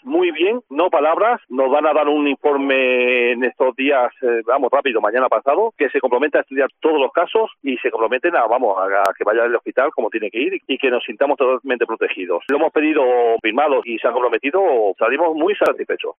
muestra su satisfacción en declaraciones a COPE Salamanca